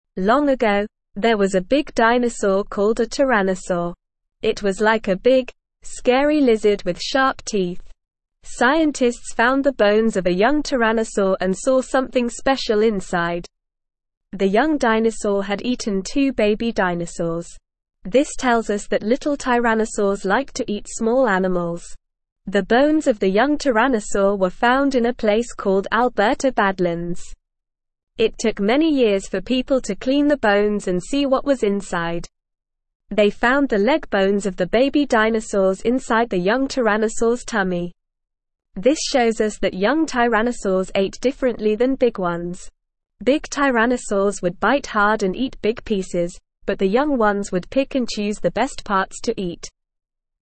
Normal
English-Newsroom-Beginner-NORMAL-Reading-Young-Tyrannosaurs-Ate-Baby-Dinosaurs-Scientists-Discover.mp3